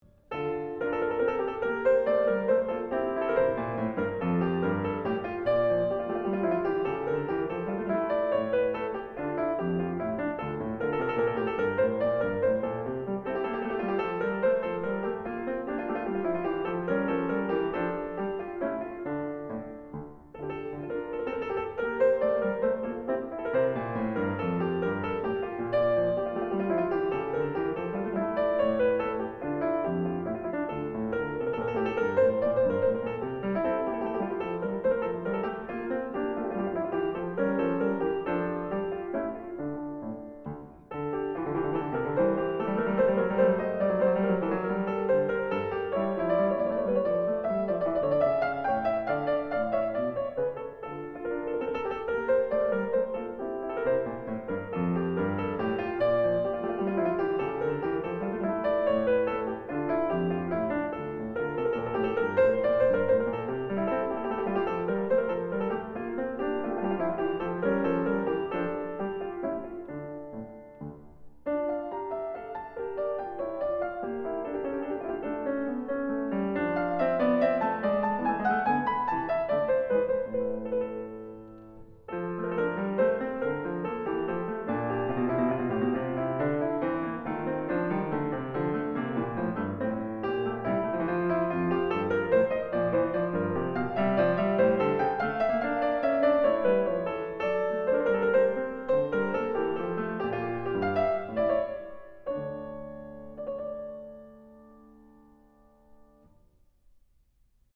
古典音樂
琴音清脆鏗鏘